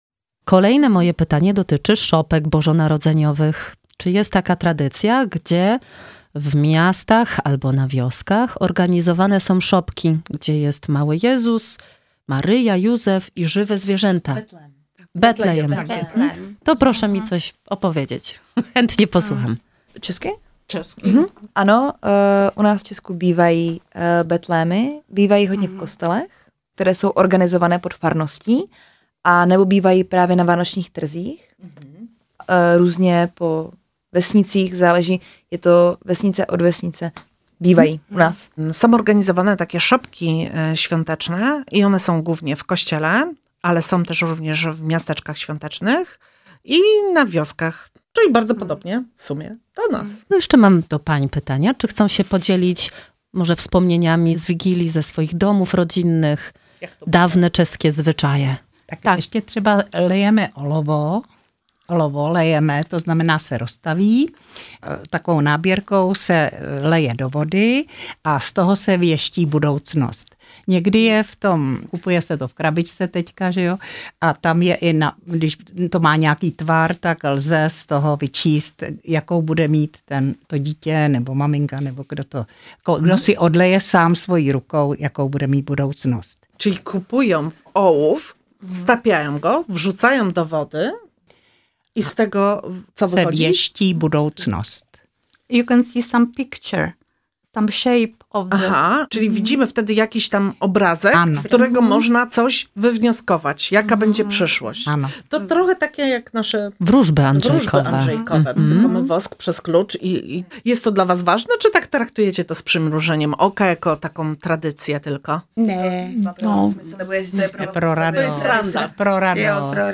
Panie poznały miasto i Ostrów Tumski, opowiadając o zwyczajach, kolędach Bożego Narodzenia oraz świątecznej kuchni prosto z Moraw!